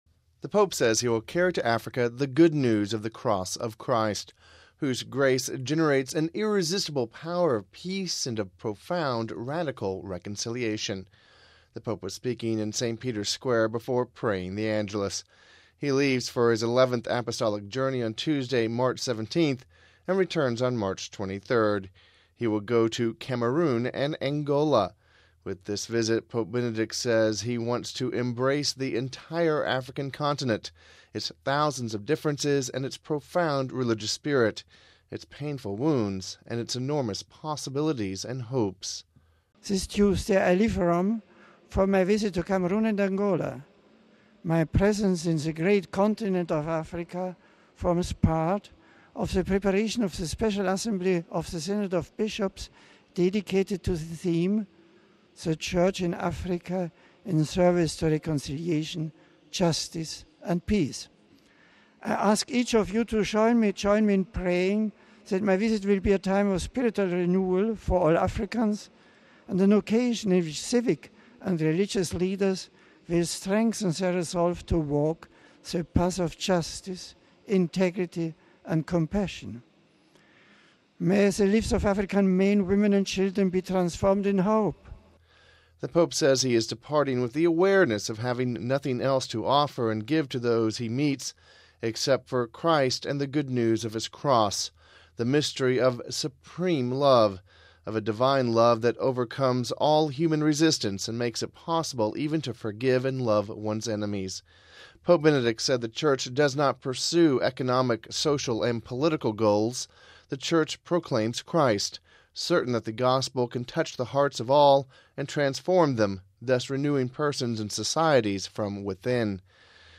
Home Archivio 2009-03-15 16:38:10 Pope Benedict Speaks About Africa Trip During Angelus (15 Mar 09 - RV) Pope Benedict spoke about his upcoming voyage to Cameroon and Angola during his Angelus message on Sunday. We have this report...